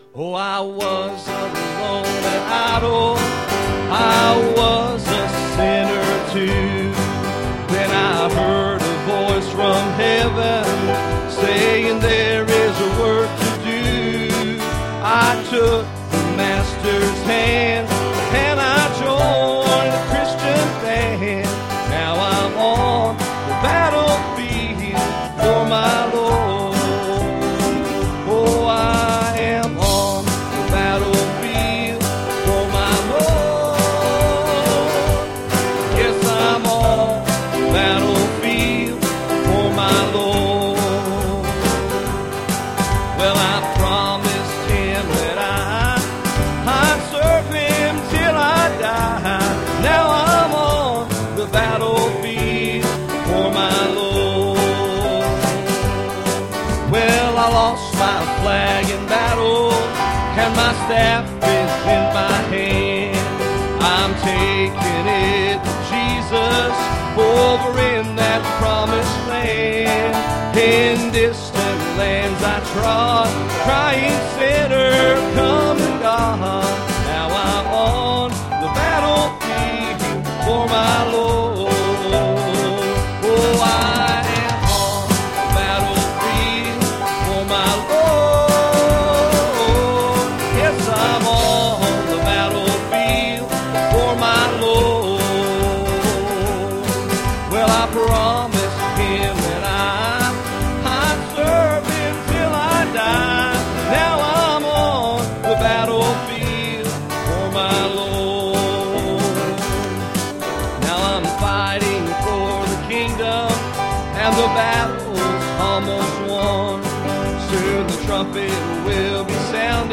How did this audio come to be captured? Series: Sunday Morning Services Passage: Judges 16;16 Service Type: Sunday Morning